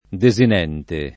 [ de @ in $ nte ]